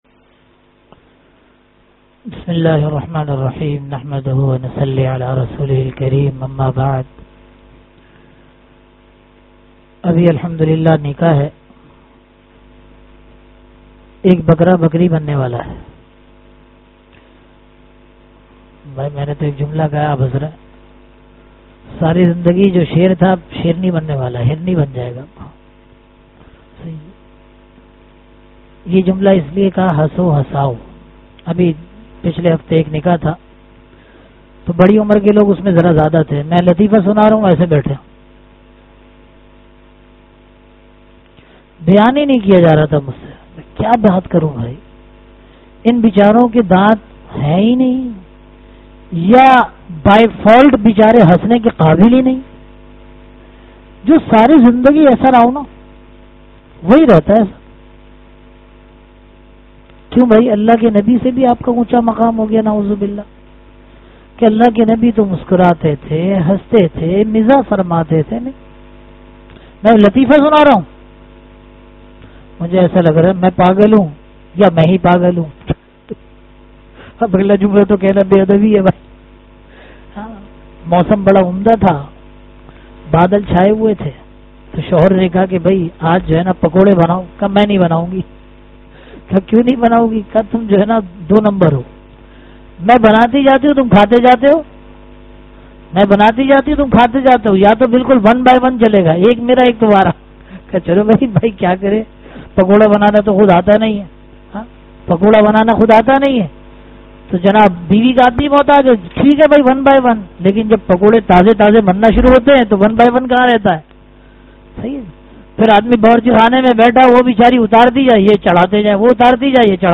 Bayanat
khusgwar zindagi ke rahnuma usool (bad jummah nikah ke moqe par byan)